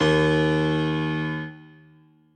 b_basspiano_v127l1o3dp.ogg